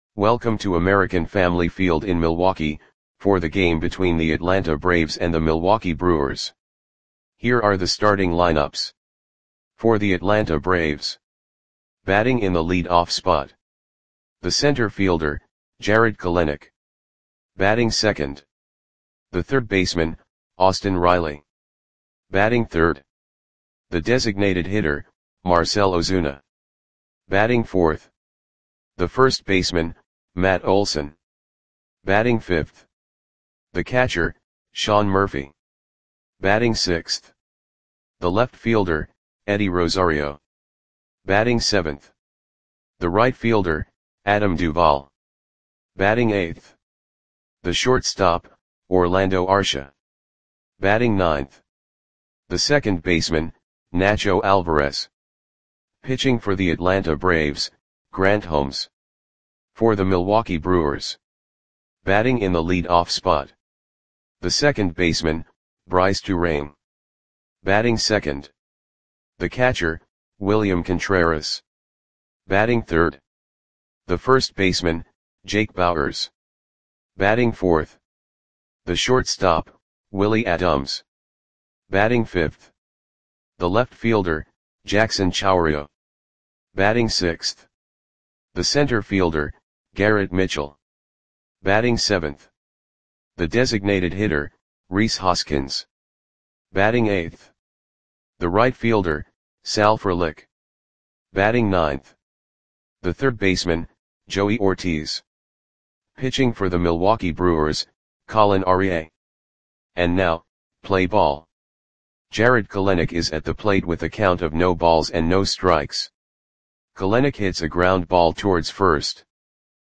Audio Play-by-Play for Milwaukee Brewers on July 29, 2024
Click the button below to listen to the audio play-by-play.